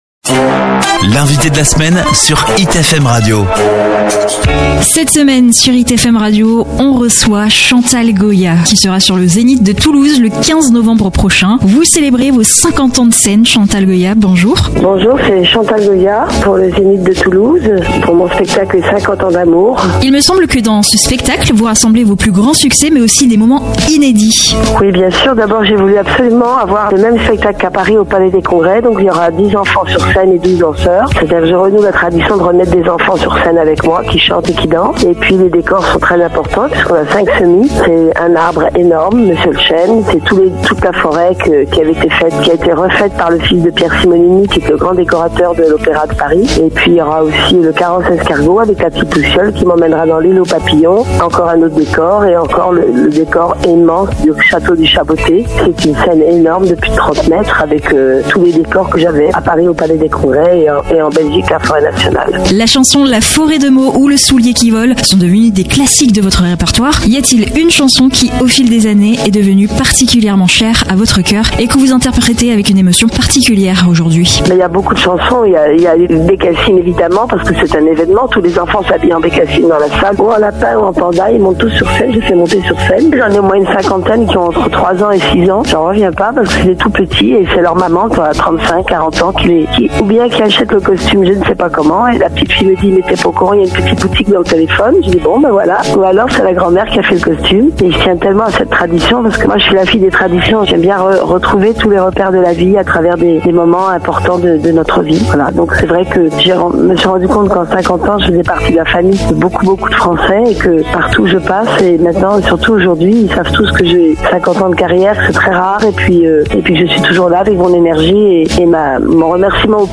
Cette semaine, nous avons eu le plaisir d’échanger avec Chantal Goya, icône de la chanson française, qui célèbre ses 50 ans de scène avec son spectacle 50 ans d’amour.